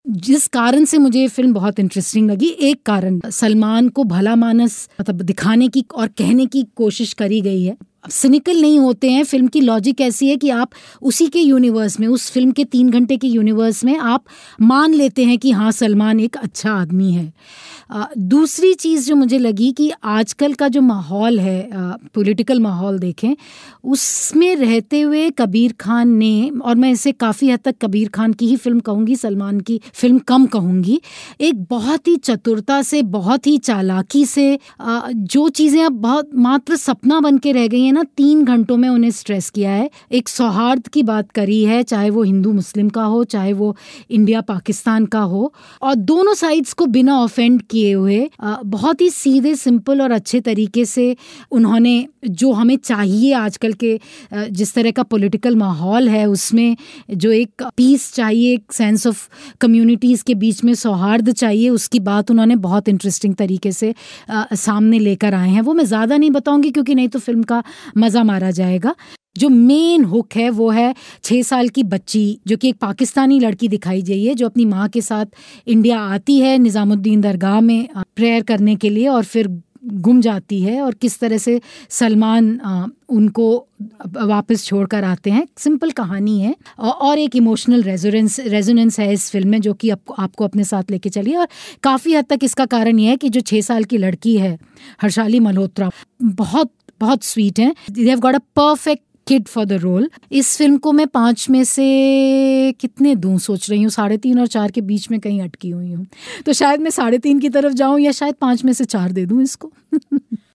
फ़िल्म 'बजरंगी भाईजान' की समीक्षा.